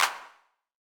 INT Clap.wav